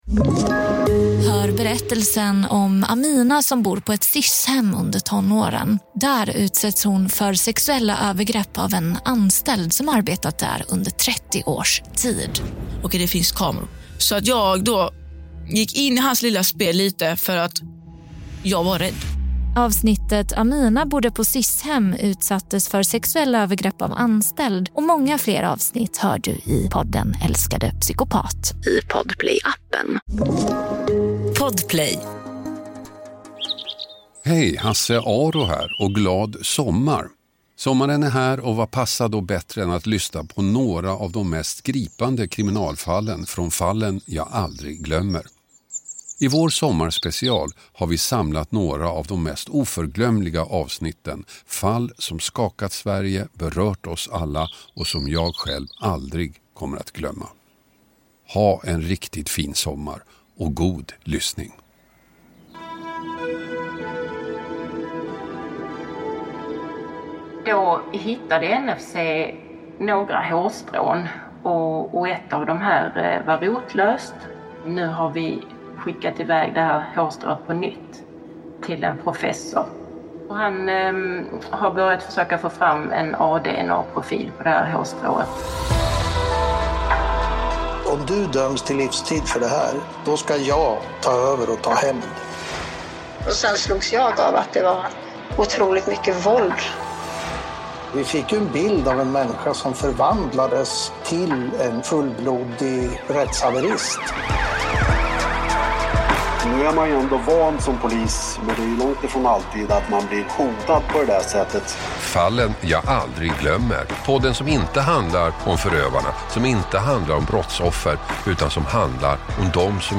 Hasse Aro intervjuar